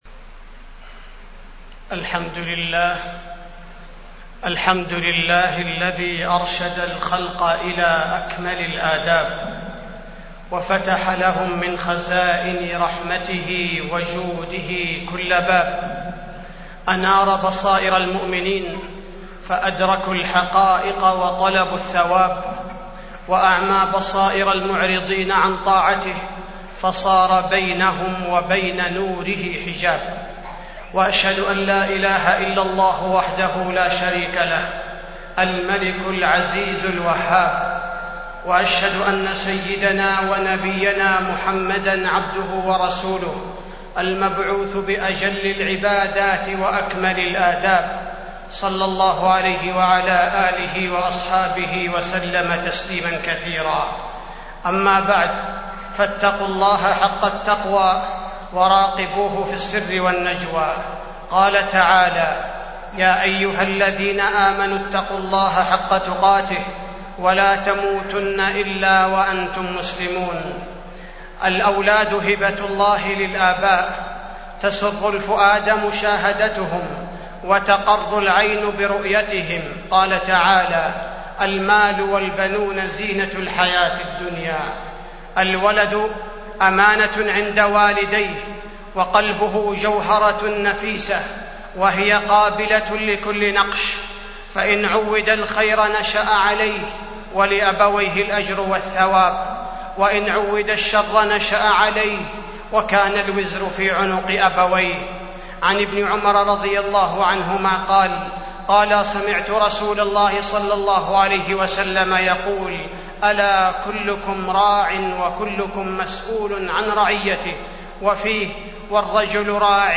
تاريخ النشر ٢٠ رجب ١٤٢٨ هـ المكان: المسجد النبوي الشيخ: فضيلة الشيخ عبدالباري الثبيتي فضيلة الشيخ عبدالباري الثبيتي تربية الولد